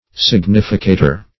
significator - definition of significator - synonyms, pronunciation, spelling from Free Dictionary
Search Result for " significator" : The Collaborative International Dictionary of English v.0.48: Significator \Sig"ni*fi*ca`tor\, n. [Cf. F. significateur.]